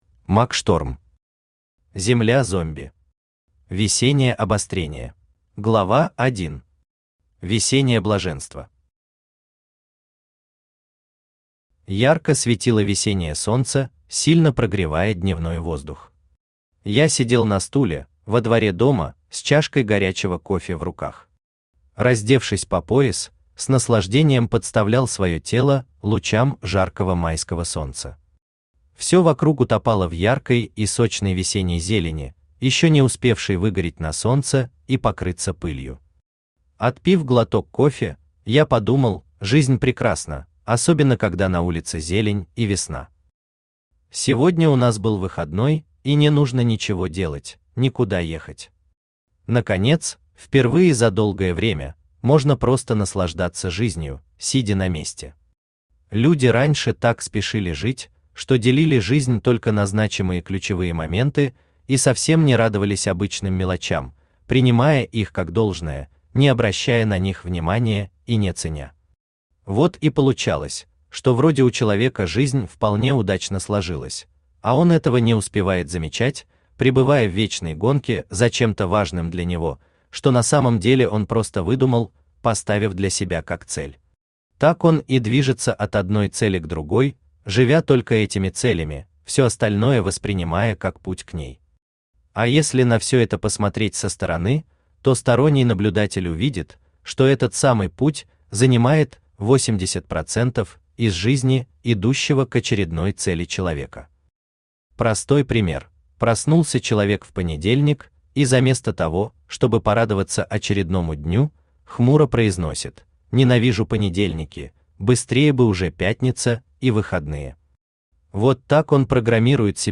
Весеннее обострение Автор Мак Шторм Читает аудиокнигу Авточтец ЛитРес.